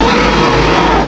cry_not_rhyperior.aif